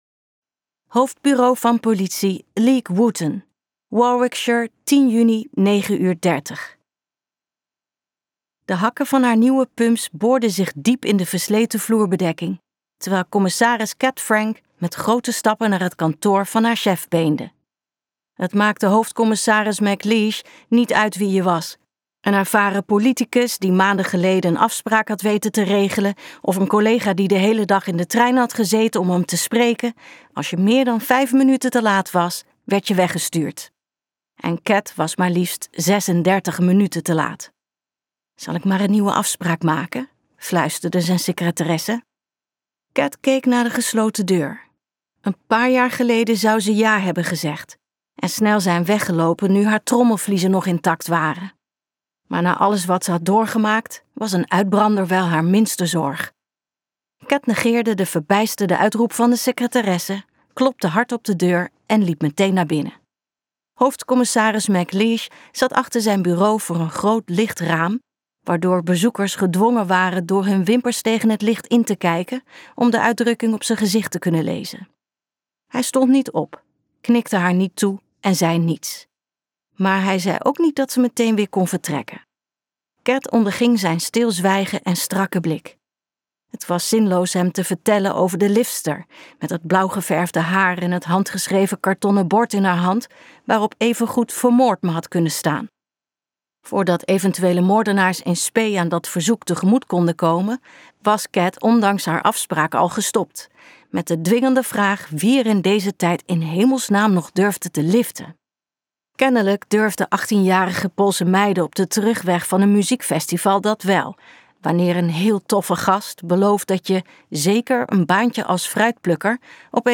Ambo|Anthos uitgevers - In een oogwenk luisterboek